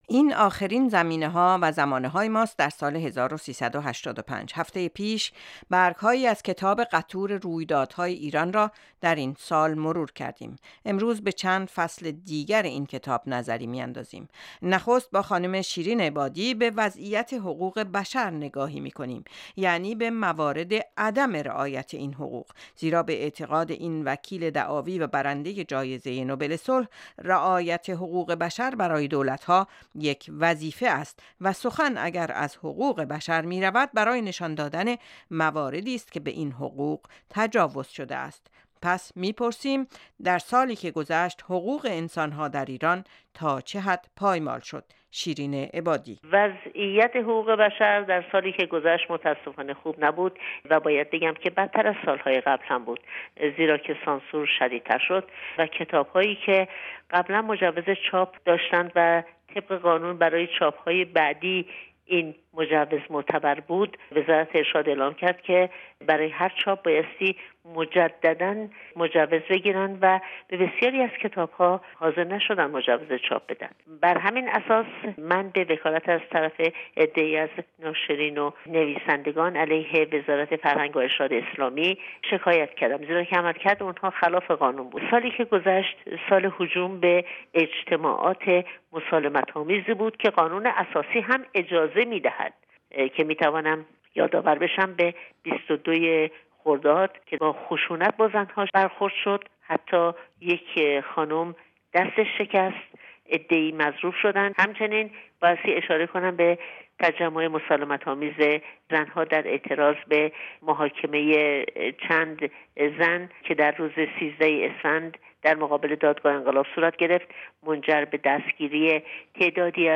مروری بر وقايع سال گذشته (قسمت دوم) گفتگوئی